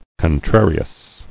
[con·trar·i·ous]